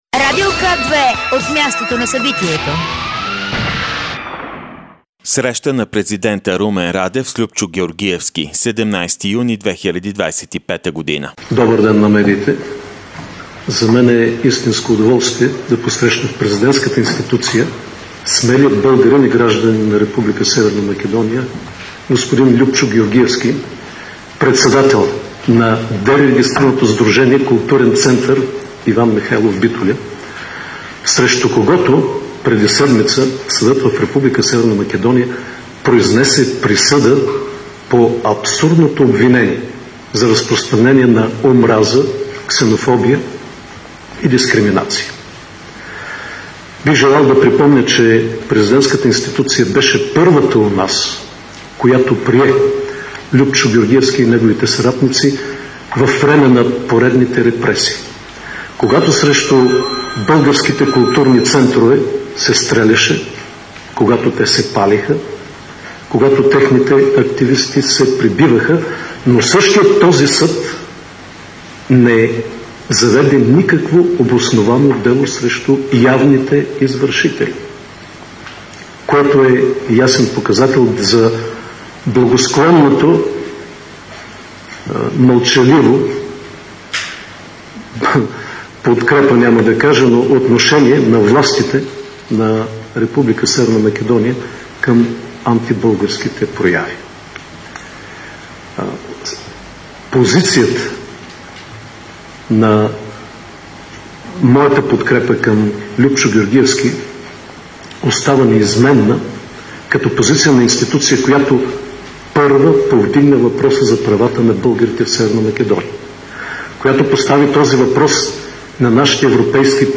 Директно от мястото на събитието
13.40 - Среща на президента Румен Радев с Люпчо Георгиевски. - директно от мястото на събитието („Дондуков" 2)